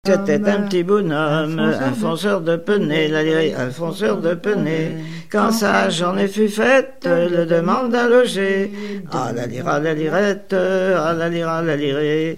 Mémoires et Patrimoines vivants - RaddO est une base de données d'archives iconographiques et sonores.
Genre laisse
Chansons et commentaires
Pièce musicale inédite